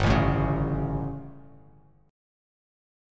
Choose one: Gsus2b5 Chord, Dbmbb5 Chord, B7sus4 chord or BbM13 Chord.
Gsus2b5 Chord